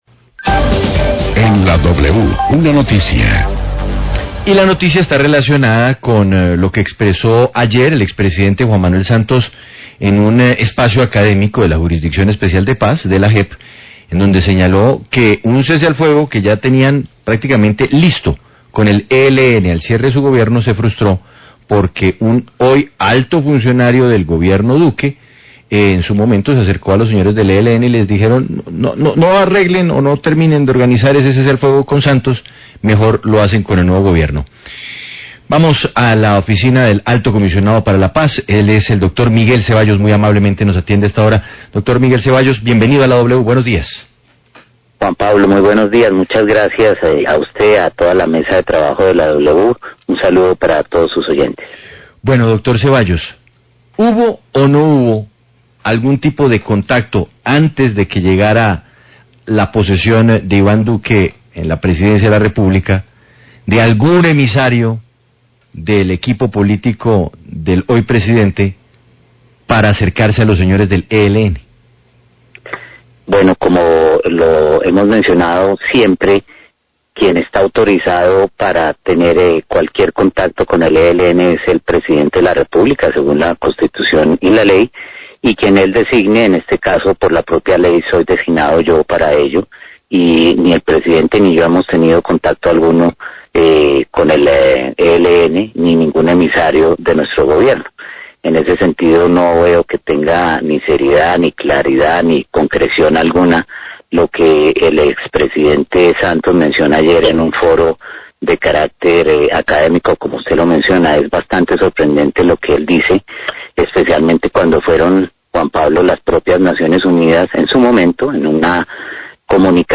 Radio
Entrevista con el Alto Comisionado de Paz, Miguel Ceballos, quien rechaza las declaraciones del expresidente Juan Manuel Santos sobre el saboteo que realizó un vocero del Centro Democrático para que no firmaran un cese al fuego con el ELN y esperara al siguiente gobierno.